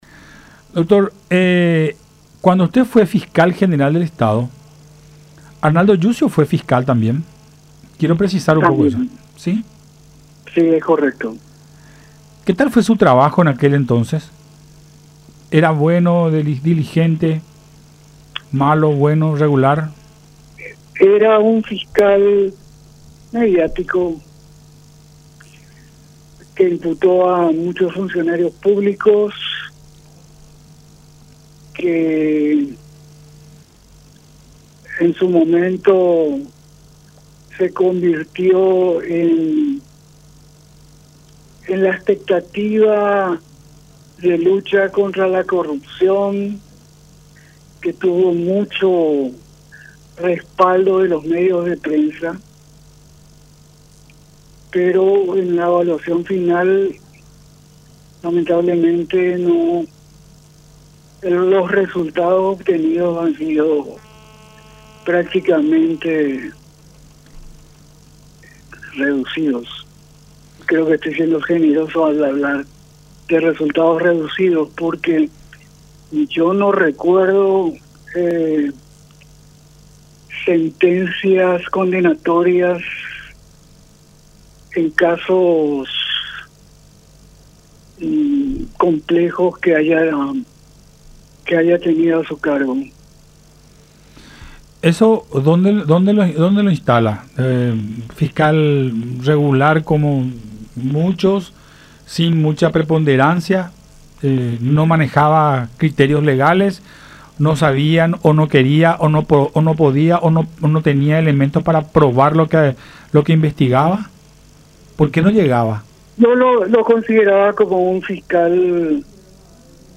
Vivimos en un país absolutamente descontrolado”, expresó Latorre en diálogo con Buenas Tardes La Unión.